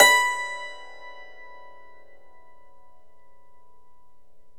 Index of /90_sSampleCDs/E-MU Formula 4000 Series Vol. 4 – Earth Tones/Default Folder/Hammer Dulcimer
DLCMR B3-L.wav